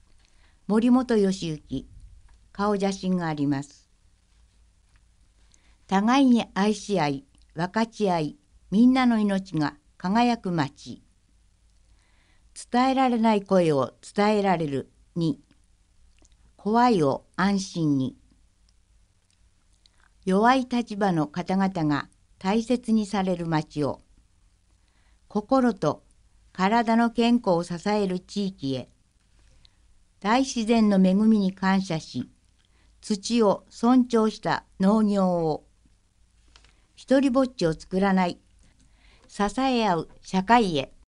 越前市議会議員補欠選挙の選挙公報（音訳）はこちら
（※選挙公報の音訳は音訳ボランティア「きくの会」の皆さんのご協力のもと配信しています。）